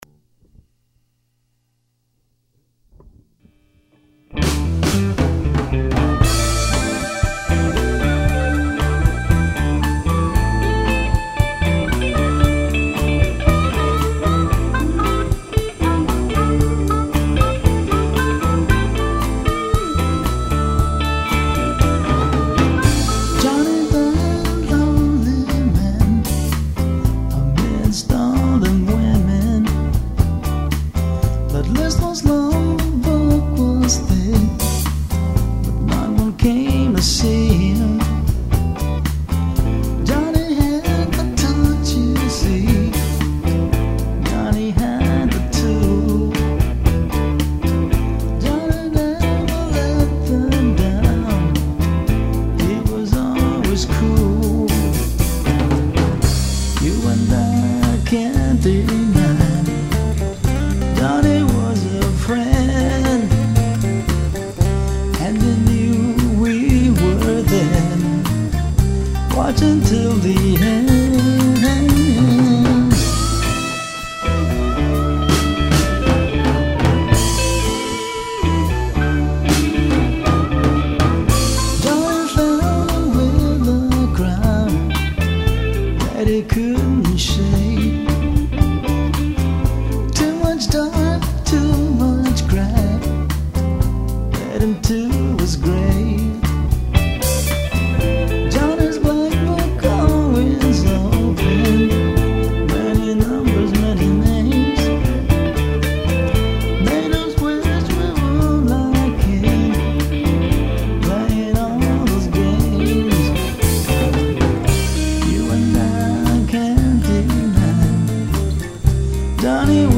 Its got a catchy guitar lick and a great harmonica intro....